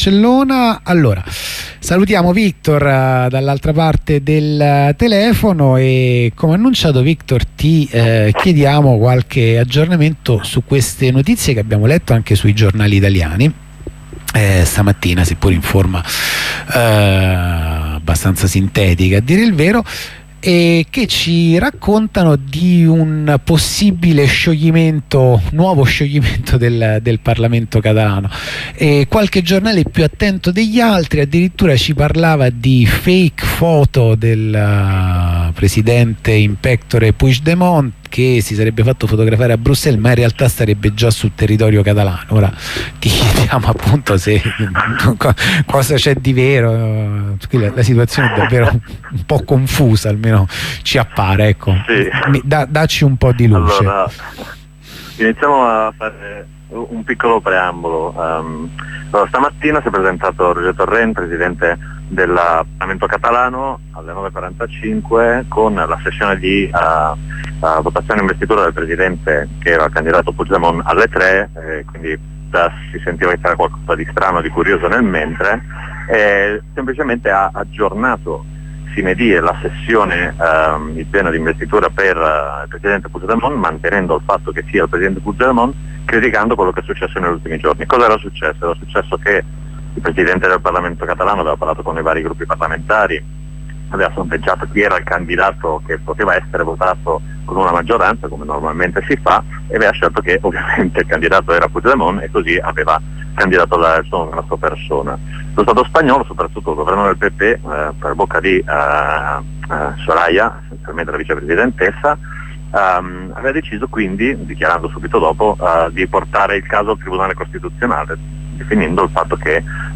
La prima corrispondenza della giornata, in diretta dal Ministero della Sanità